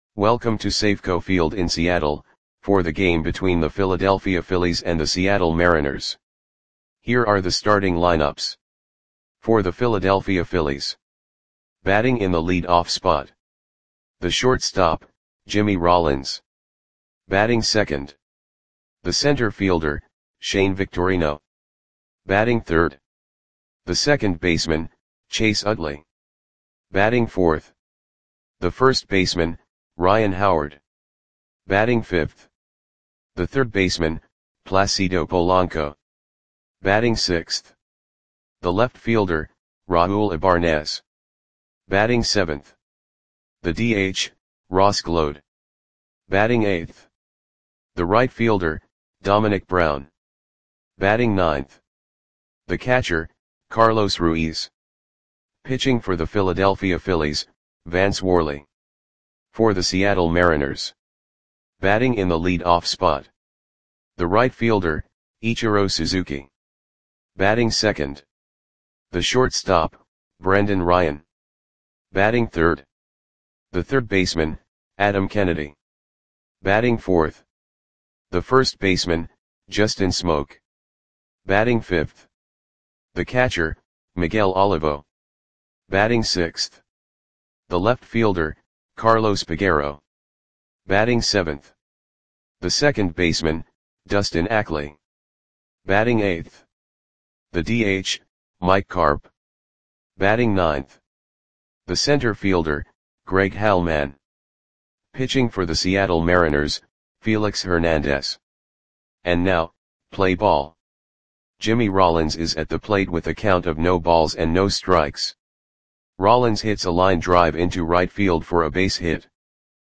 Audio Play-by-Play for Seattle Mariners on June 18, 2011
Click the button below to listen to the audio play-by-play.